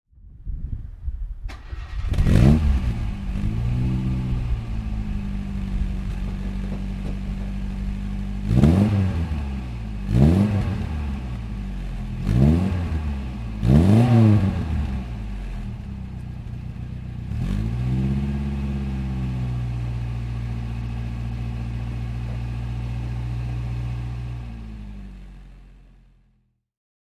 Lancia Appia Convertibile (1959) - Starten und Leerlauf
Lancia_Appia_1959.mp3